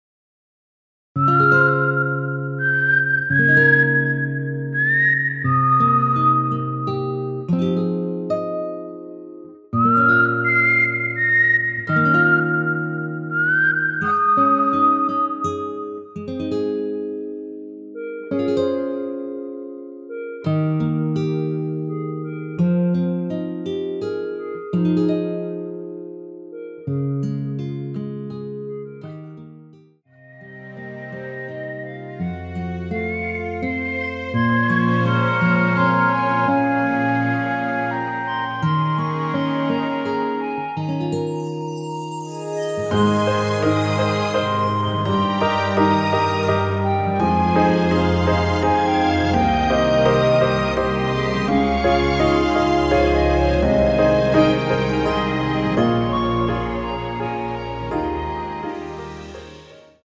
무반주 구간 들어가는 부분과 박자 맞출수 있게 쉐이커로 박자 넣어 놓았습니다.(일반 MR 미리듣기 참조)
Ab
앞부분30초, 뒷부분30초씩 편집해서 올려 드리고 있습니다.
중간에 음이 끈어지고 다시 나오는 이유는
(멜로디 MR)은 가이드 멜로디가 포함된 MR 입니다.